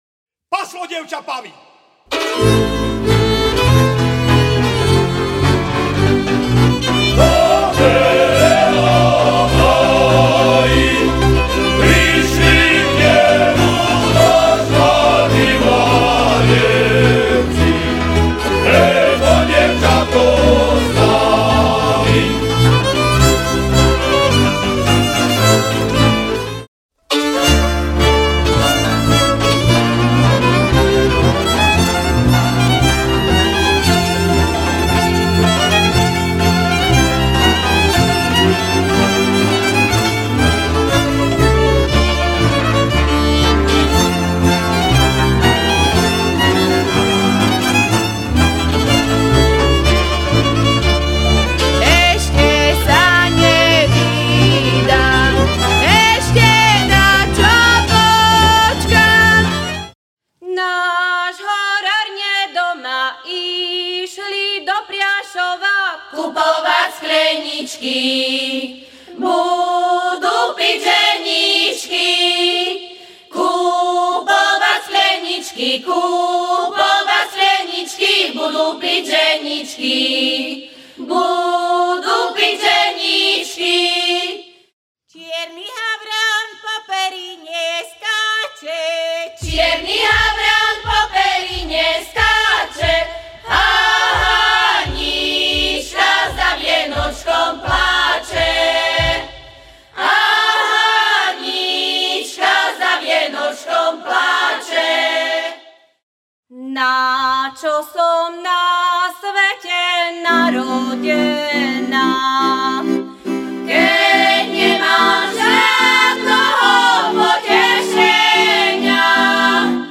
ľudovou hudbou
Ukážky z albumu: